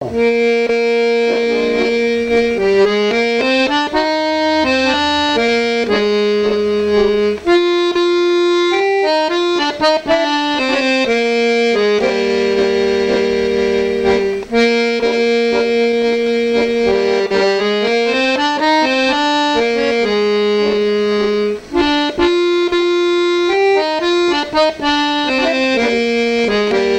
danse : tango
Pièce musicale inédite